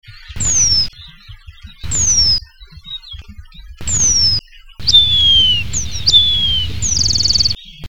Rémiz penduline
Remiz pendulinus